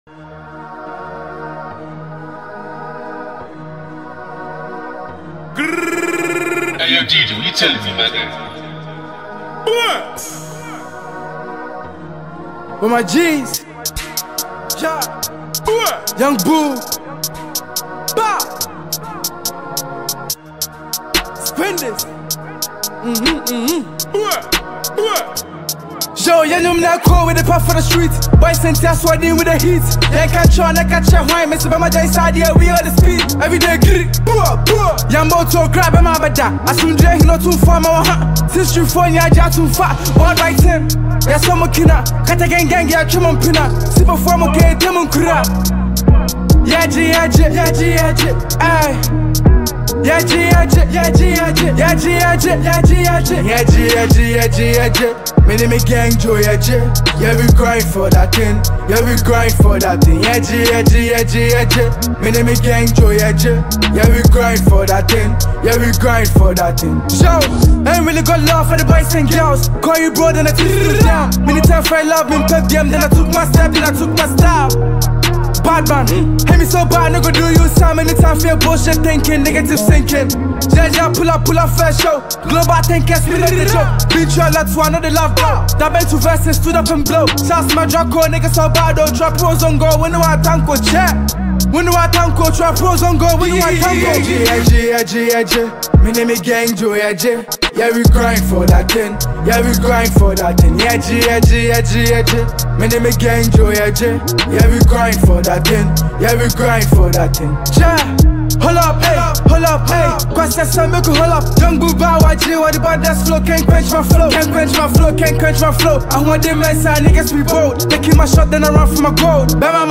wavy drill